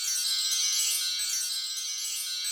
NewMario2_GoldEffect.wav